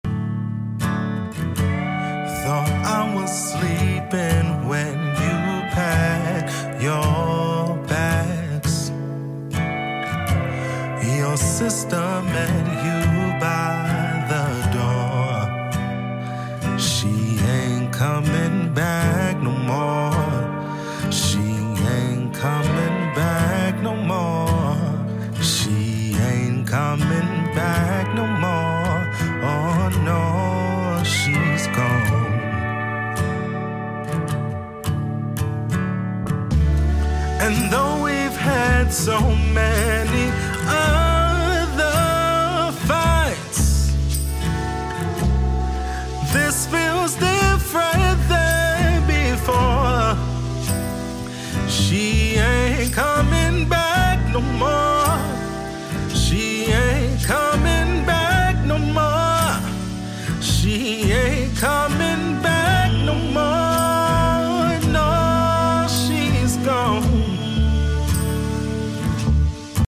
RNB music that makes you feel something.